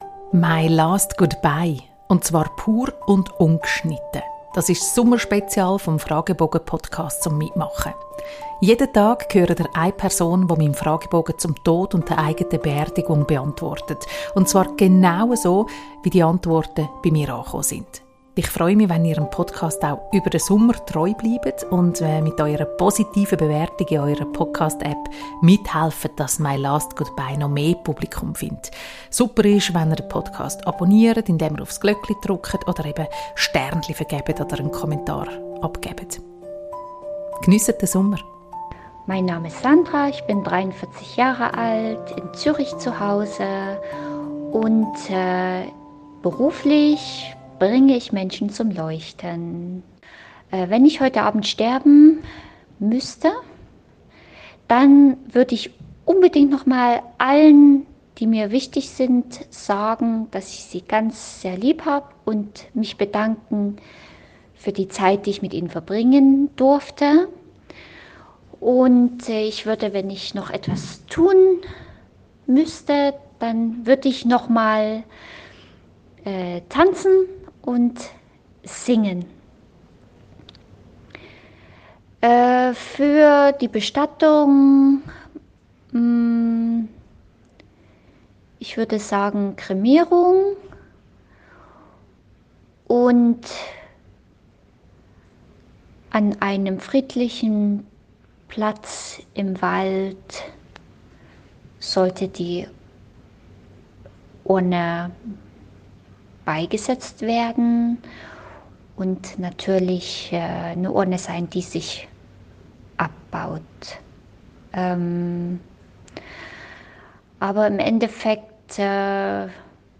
MY LAST GOODBYE - pur und ungeschnitten.